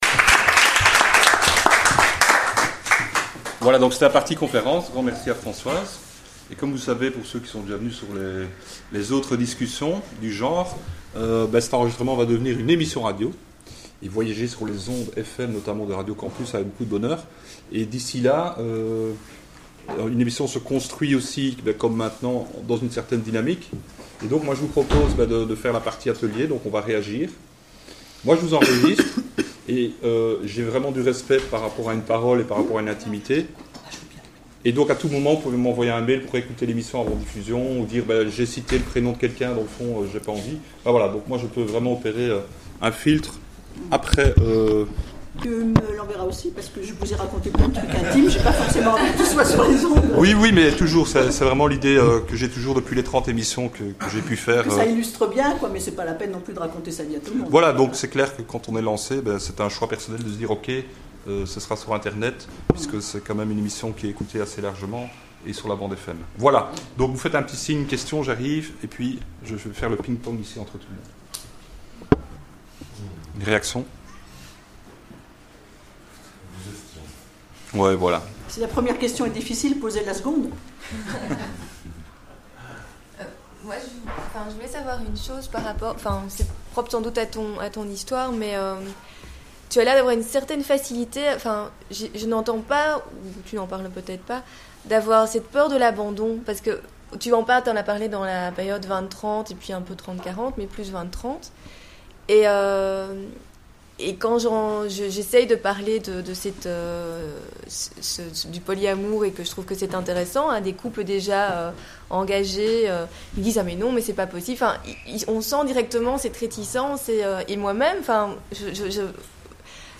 question-réponses